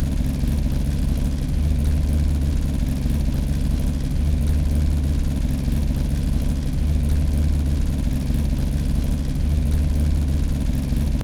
Index of /server/sound/vehicles/lwcars/Detomaso_pantera
idle.wav